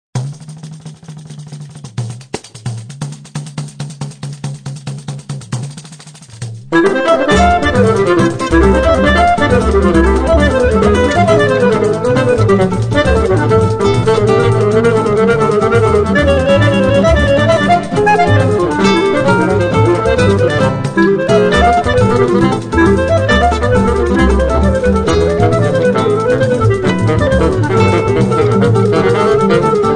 fagotto
clarinetto
chitarra
contrabbasso
percussioni